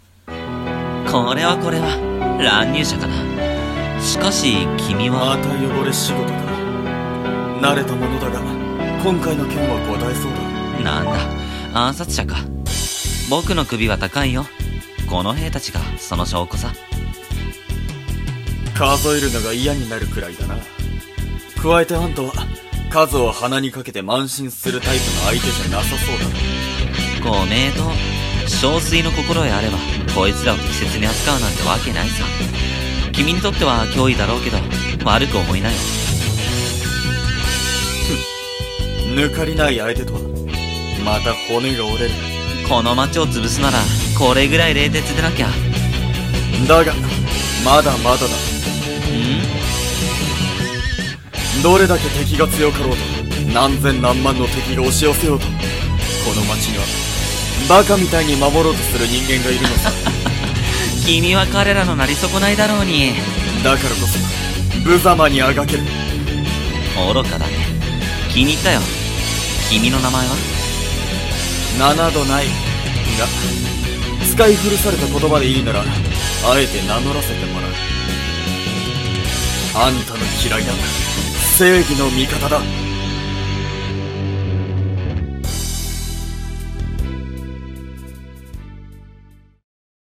声劇台本】たとえ脆き誓いでも